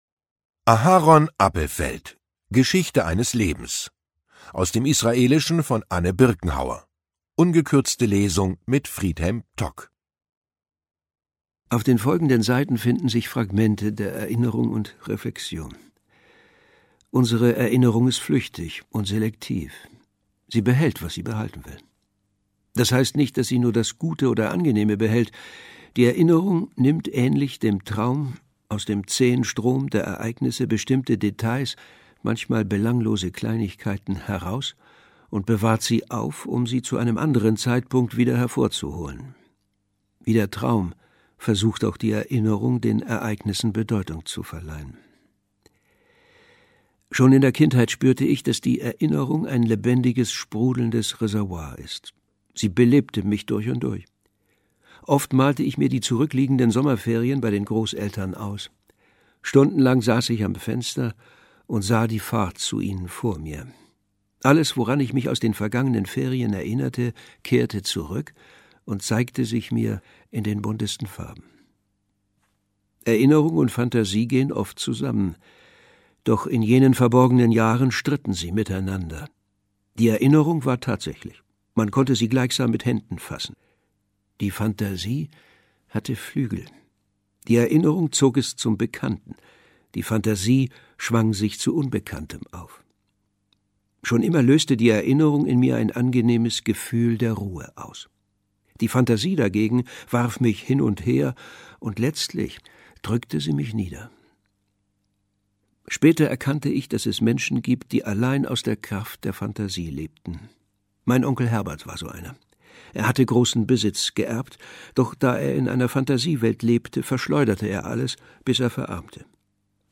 Literatur Romane / Erzählungen